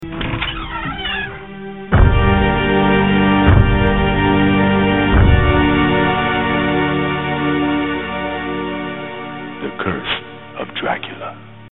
Category: Radio   Right: Personal
Tags: Radio The Curse Of Dracula Play Horror Bram Stoker